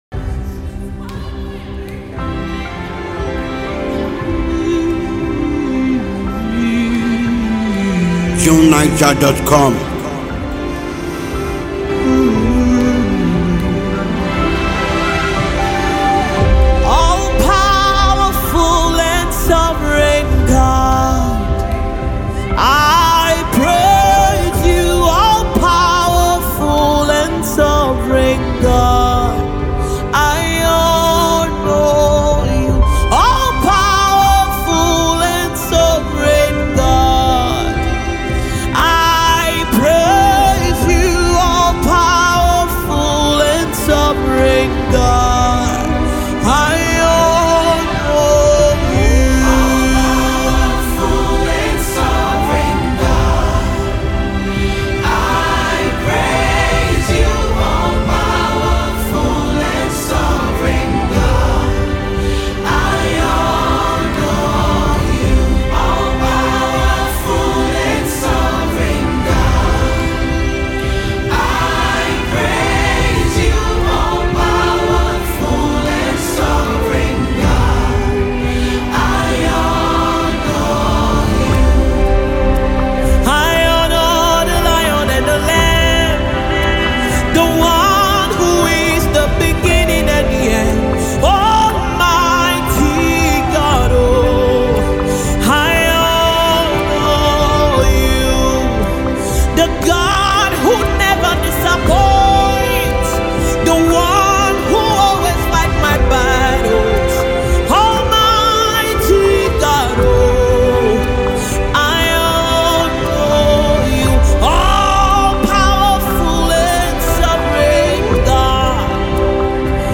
a vocally gifted Nigerian gospel singer and songwriter
Anyone looking for soul-stirring music should have it.